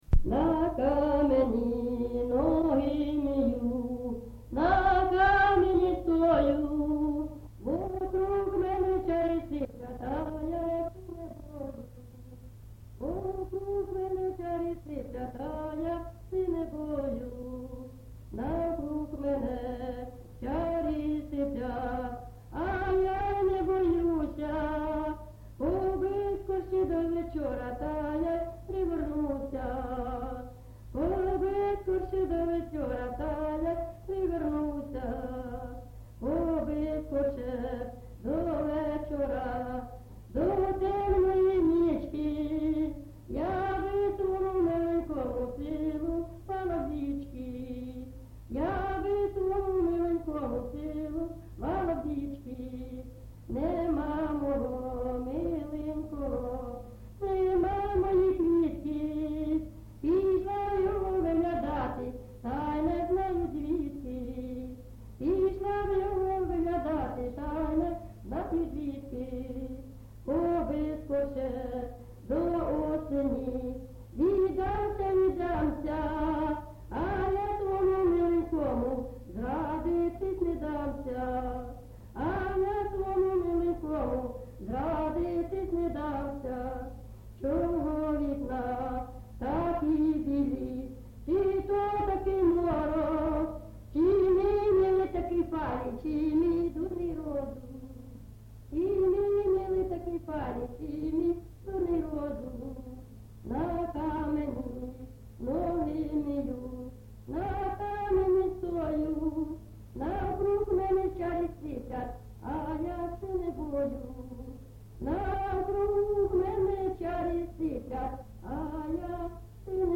ЖанрПісні з особистого та родинного життя
Місце записус. Золотарівка, Сіверськодонецький район, Луганська обл., Україна, Слобожанщина